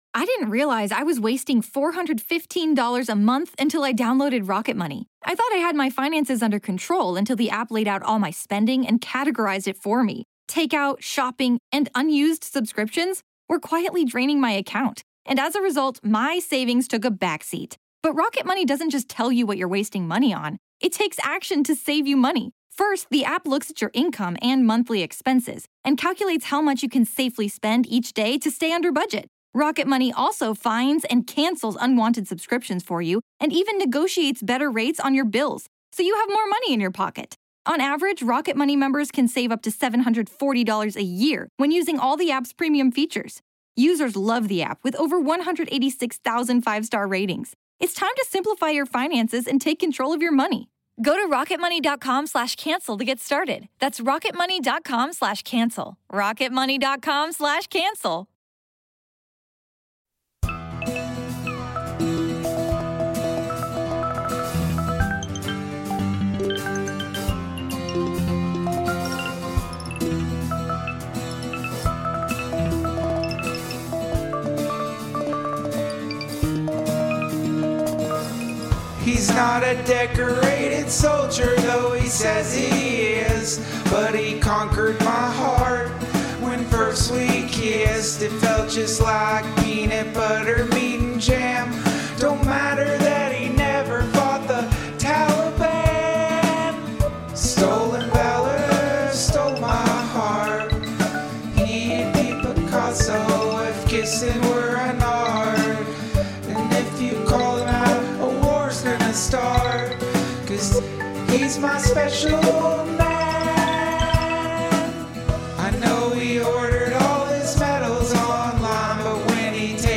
UFO and paranormal researcher, joins the DTFH!